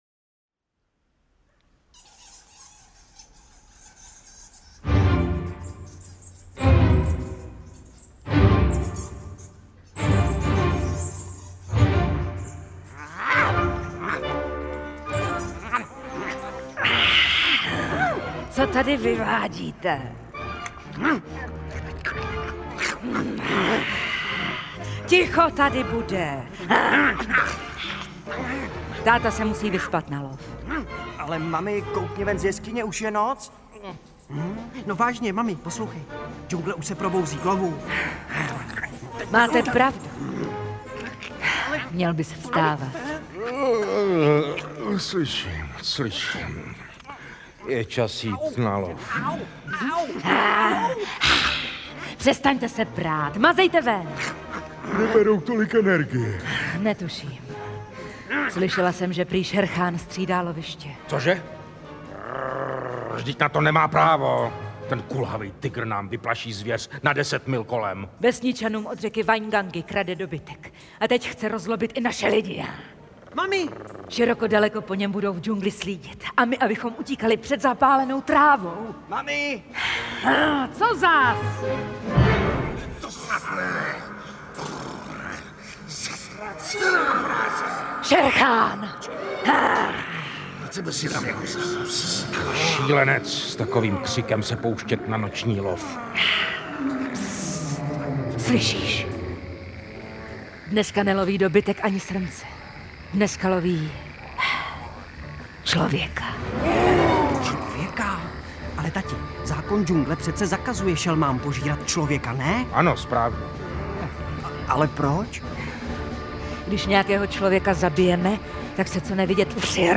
Dramatizace slavného příběhu s hudbou Symfonického orchestru Českého rozhlasu a s Janem Cinou v hlavní roli zpracovává obě dvě Knihy džunglí – život mezi zvířaty i návrat k lidem. Světoznámý příběh o přátelství, lásce, ale i o boji o přežití nás zavede do džungle, v níž se malý chlapec Mauglí bude muset naučit vlčí řeči, zákonům džungle a pravidlům slušného chování.